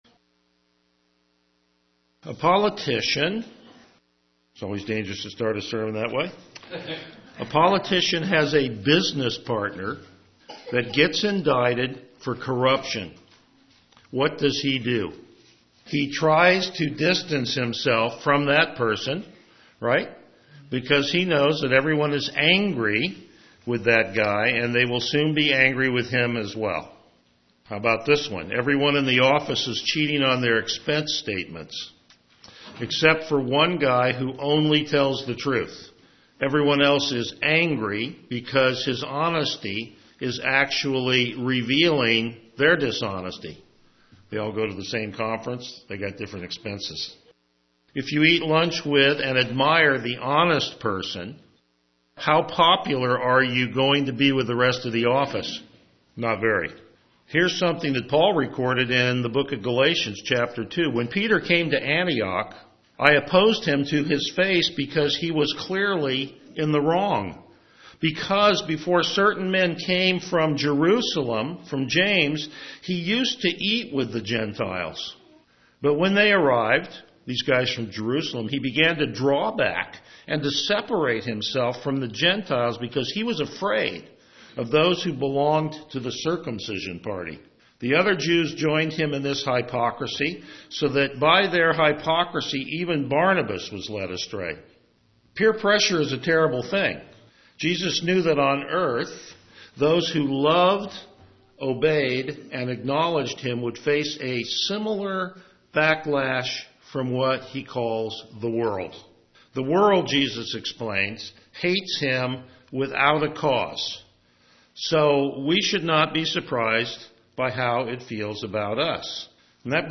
Passage: John 15:18-27 Service Type: Morning Worship
Verse By Verse Exposition